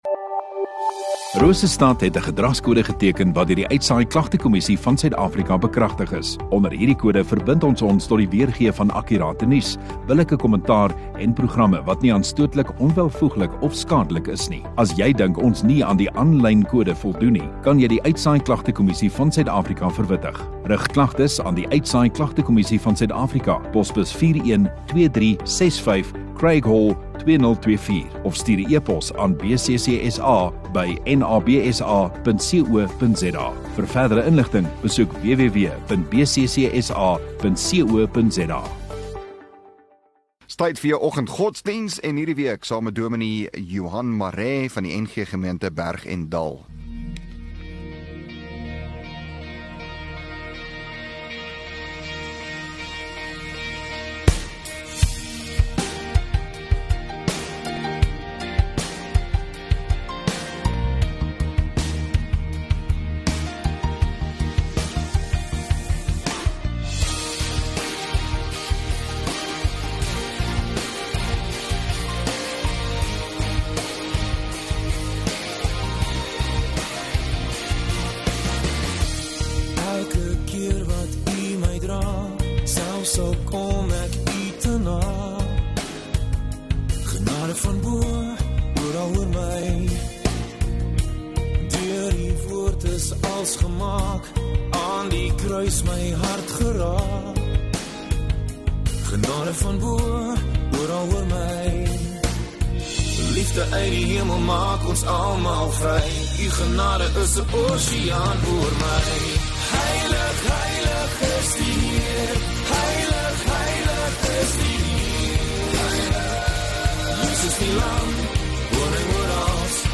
11 Jun Woensdag Oggenddiens